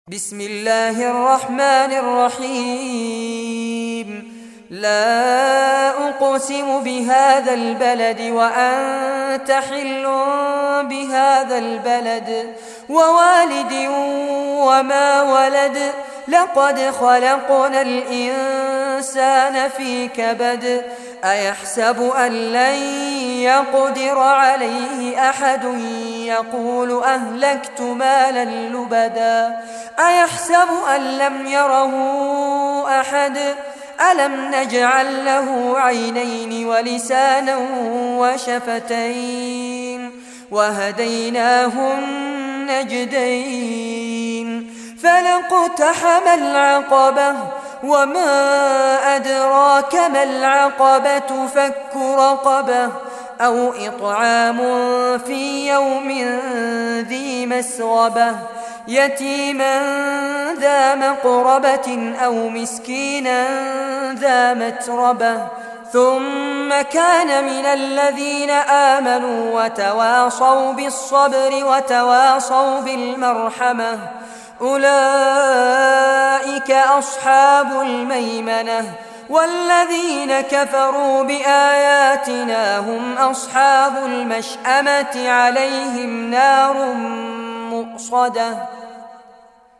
Surah Al-Balad Recitation by Sheikh Fares Abbad
Surah Al-Balad, listen or play online mp3 tilawat / recitation in Arabic in the beautiful voice of Sheikh Fares Abbad.